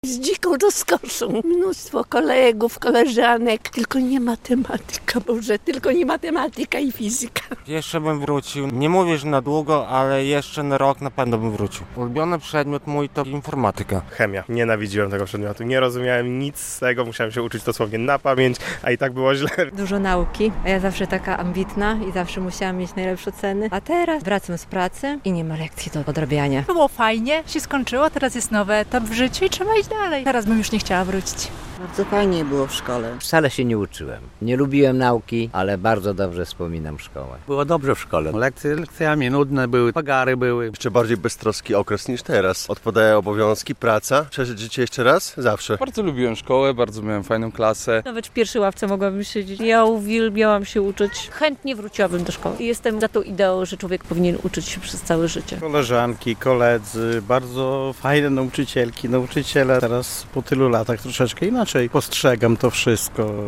Zapytani o to, czy chcieliby znów przeżyć te chwile mówią: